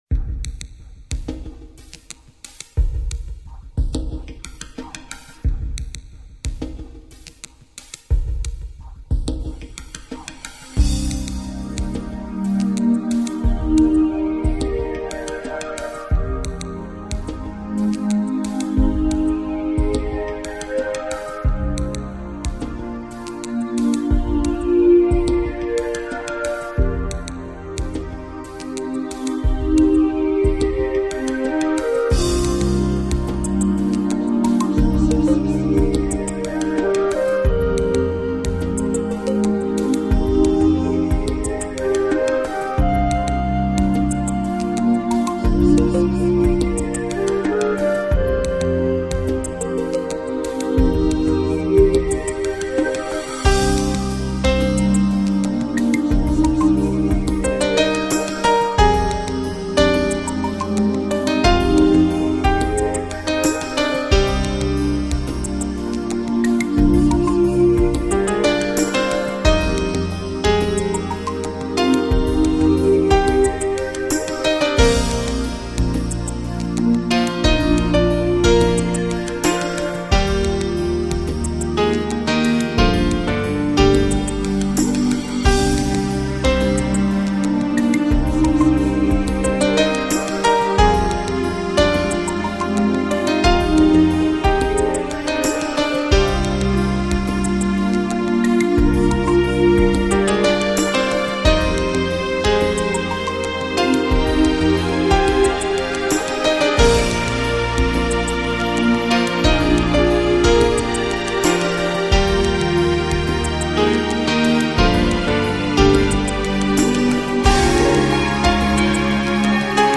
Жанр: Electronic, Lounge, Chill Out, Downtempo, Ambient